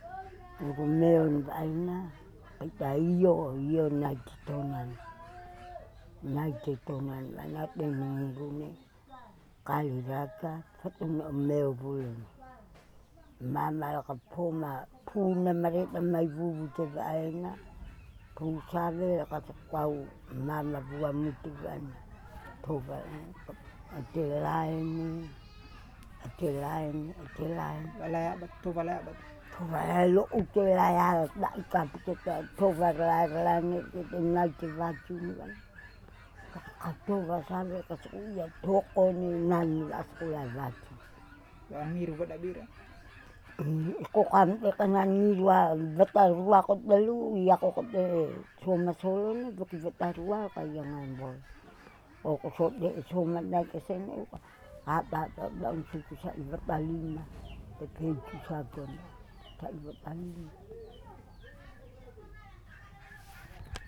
Speaker
Recording made in kampong Ndeo, Ndeo domain.